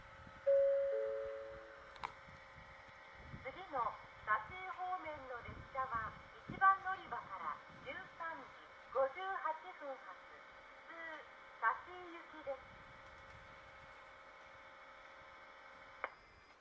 この駅では接近放送が設置されています。
１番のりばJD：香椎線
接近放送普通　香椎行き接近放送です。